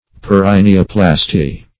Search Result for " perineoplasty" : The Collaborative International Dictionary of English v.0.48: Perineoplasty \Per`i*ne"o*plas`ty\, n. [Perineum + -plasty.]
perineoplasty.mp3